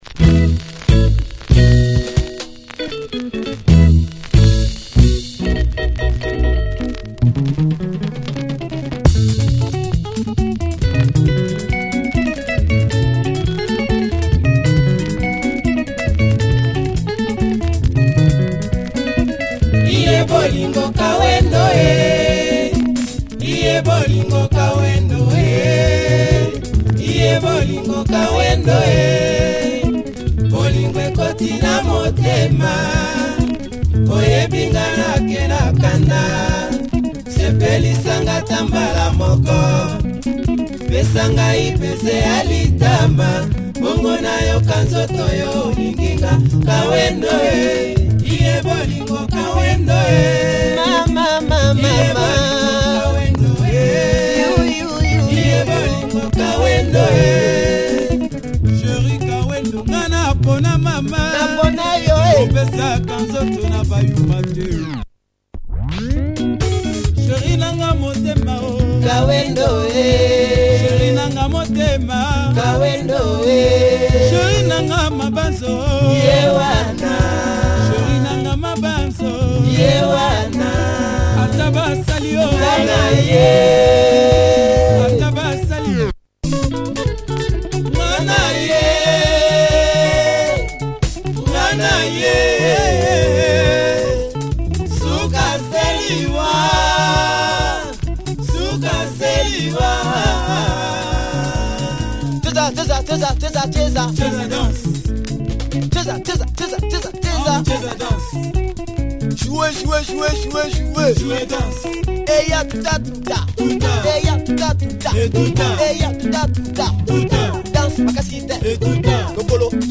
Super soukous vibe
Tight and a cool chanting vocal performance on the B-side!